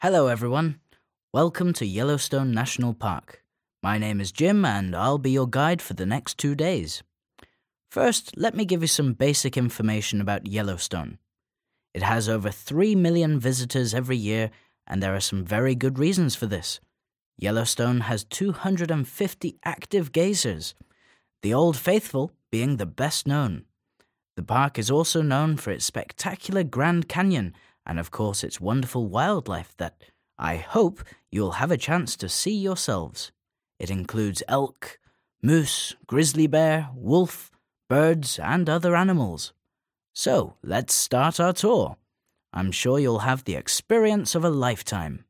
Listen to the tour guide talking to his group of tourists in Yellowstone National Park and answer the questions.